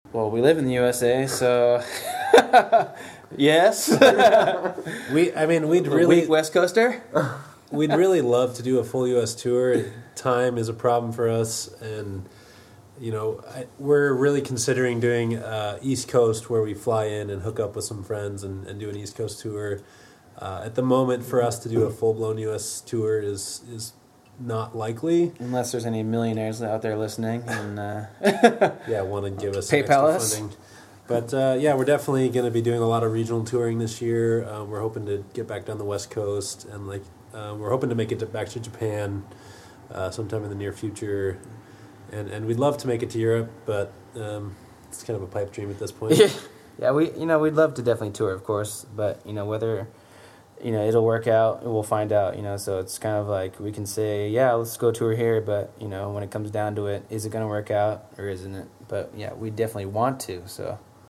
YOU MAY DIE IN THE DESERT INTERVIEW- October 2012